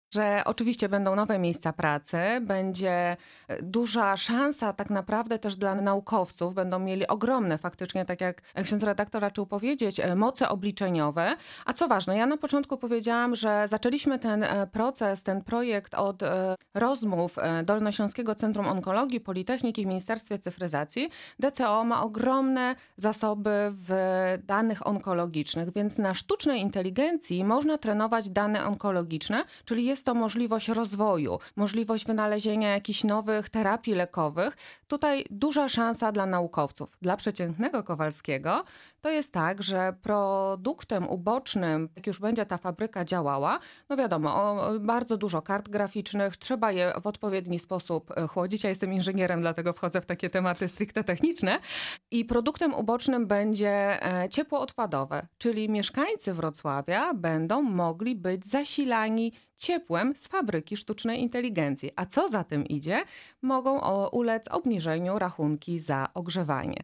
Naszym „Porannym Gościem” była posłanka na Sejm Koalicji Obywatelskiej Anna Sobolak.